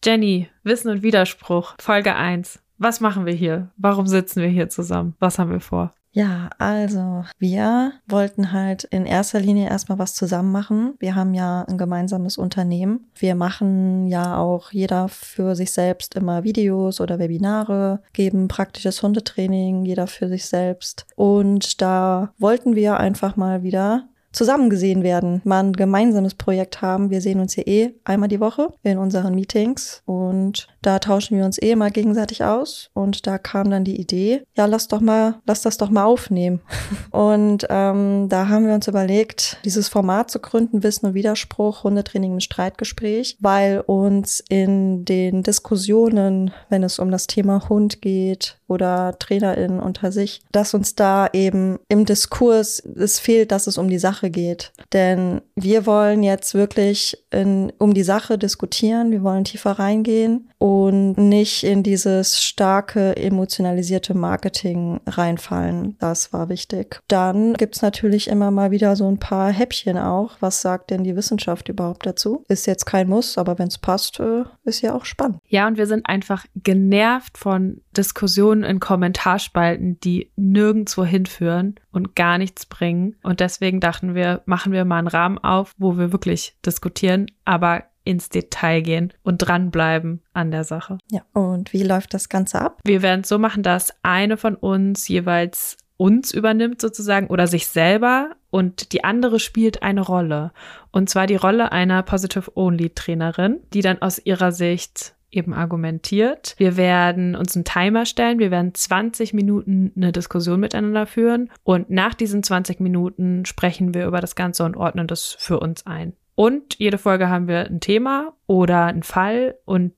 In 20 Minuten diskutieren die beiden über die Mensch-Hund-Beziehung.